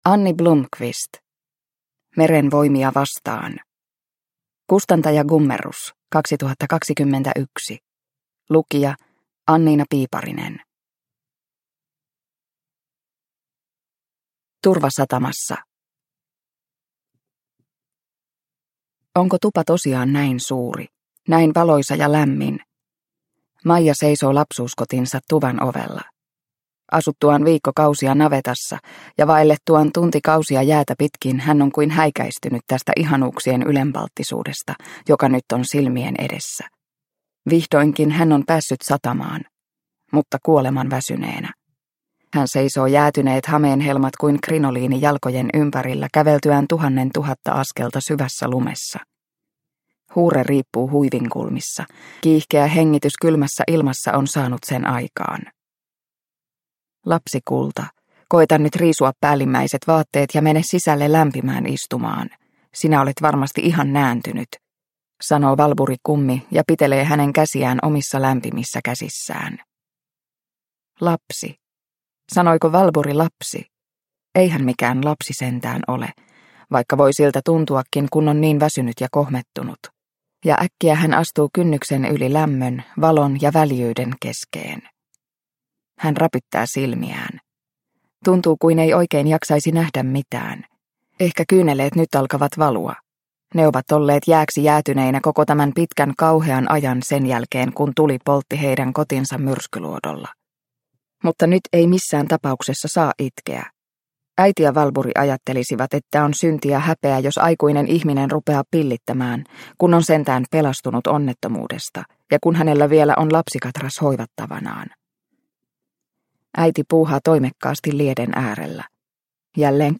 Meren voimia vastaan – Ljudbok – Laddas ner